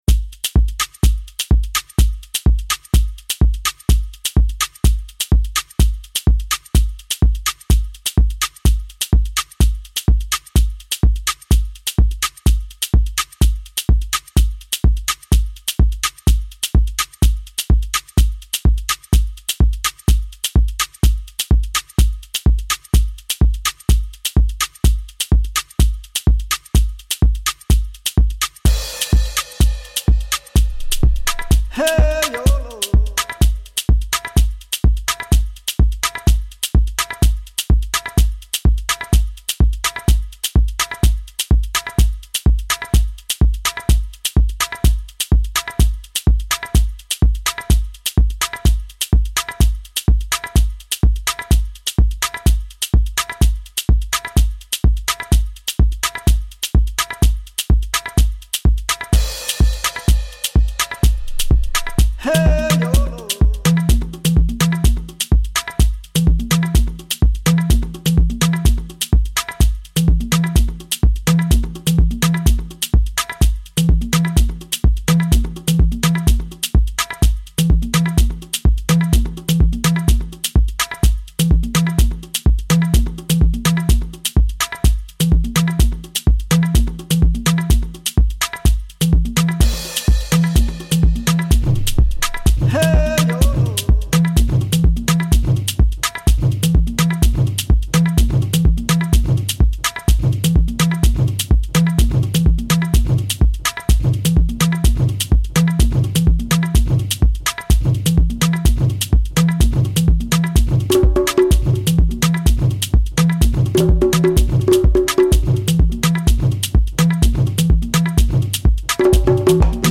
Genre: AFRICANISM.